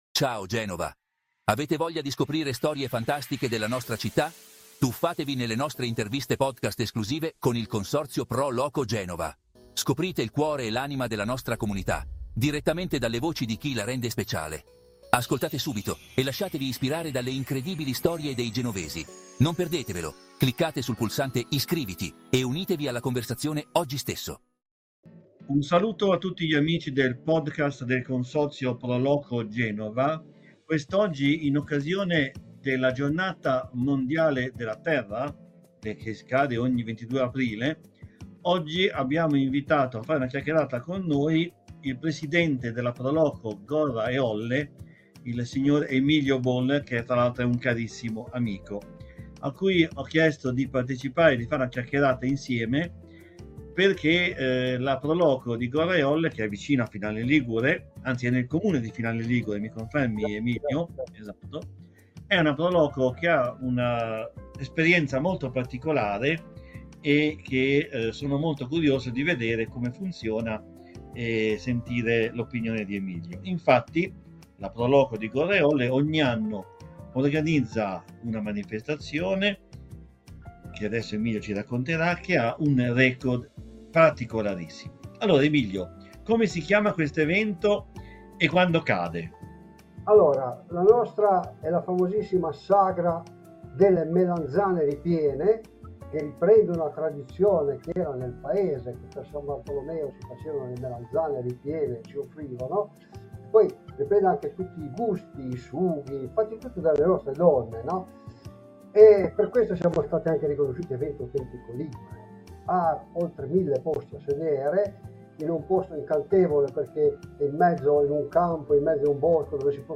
Eco Sagra delle Melanzane Ripiene – Intervista